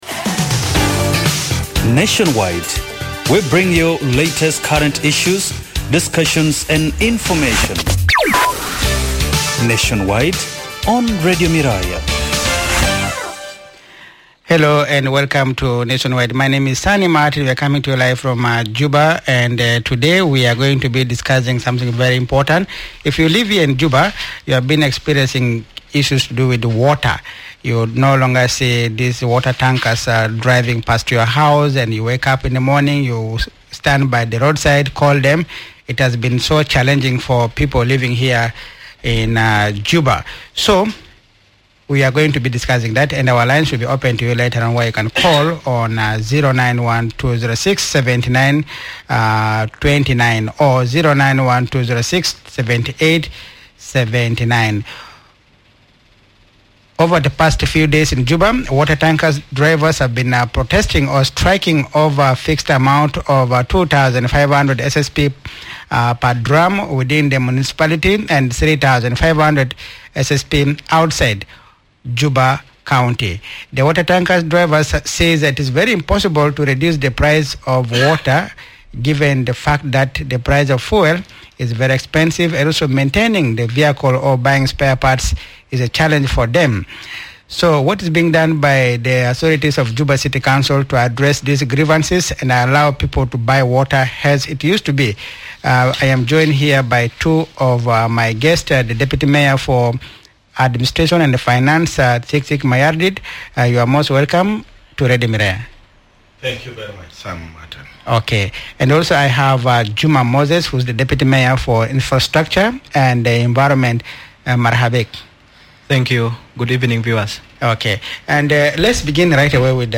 Guests: - Thiik Thiik Mayardit: Deputy Mayor for Administration and Finance, - Juma Moses : Deputy Mayor for infrastructure and Environment